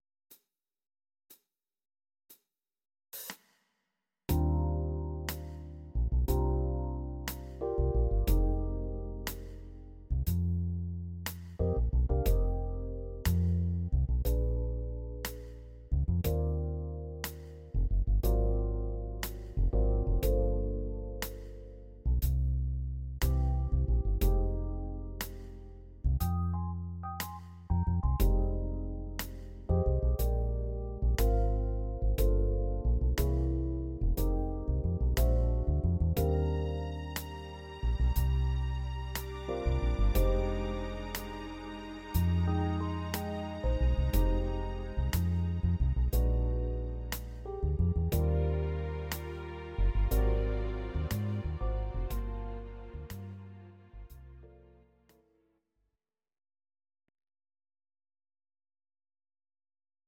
Audio Recordings based on Midi-files
Oldies, Jazz/Big Band, 1950s